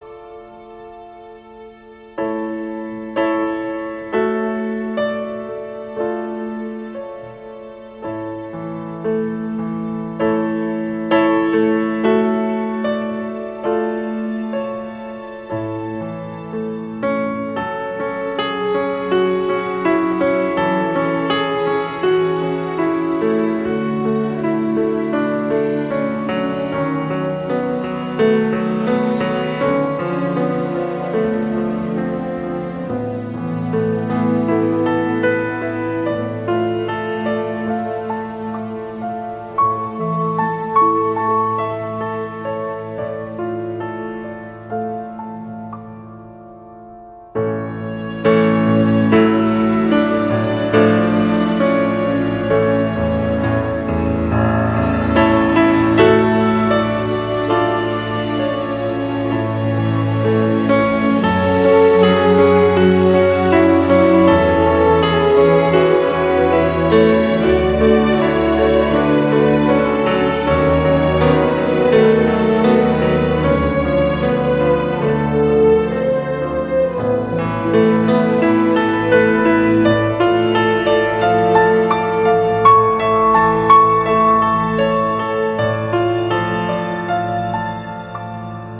partitura para piano